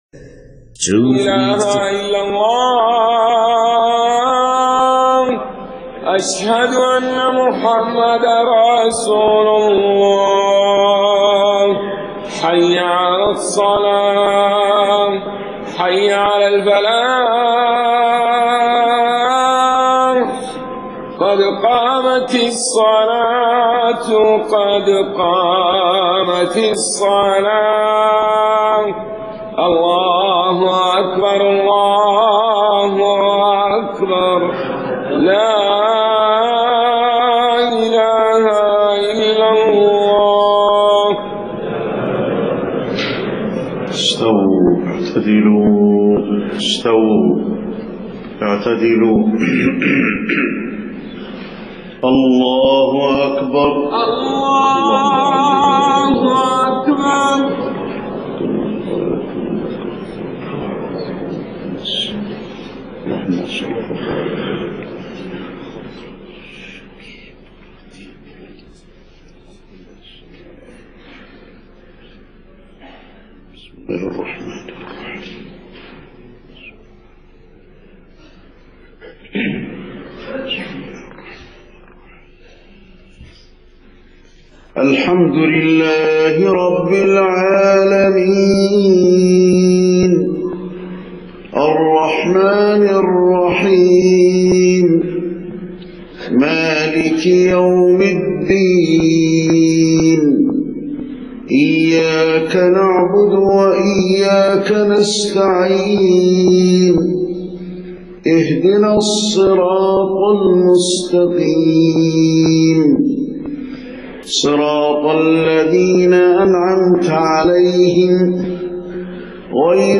صلاة الفجر 20 محرم 1430هـ سورة المطففين كاملة > 1430 🕌 > الفروض - تلاوات الحرمين